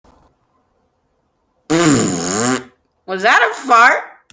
THE BEST FART
the-best-fart.mp3